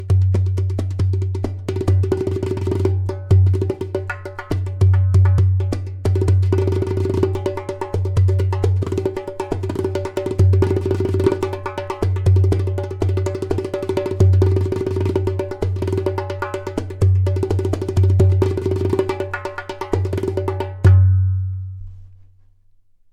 P r e m i u m Line Darbuka
In this exclusive line, materials such as clay, glaze, and natural goat skin come together in a magical harmony, giving life to a balanced, resonant sound.
• High sound clear “taks”.
• Deep bass
• Very strong clay “kik”/click sound